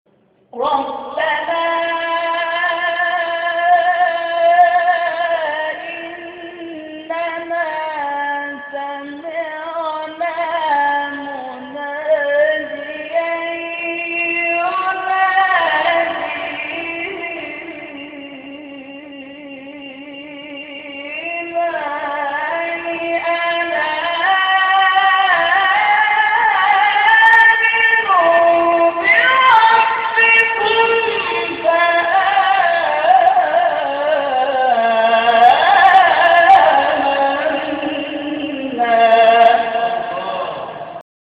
گروه فعالیت‌های قرآنی: فرازهای صوتی از قاریان ممتاز کشور ارائه می‌شود.